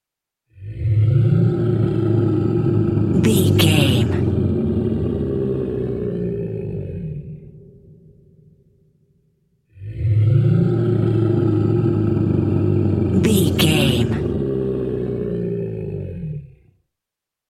Dinosaur angry scream big creature with without rvrb
Sound Effects
Atonal
ominous
eerie
angry